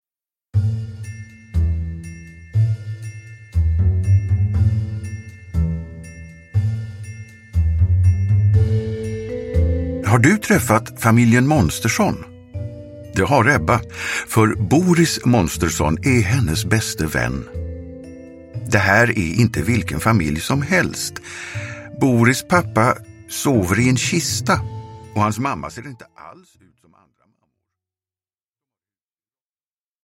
Min vän Boris – Ljudbok – Laddas ner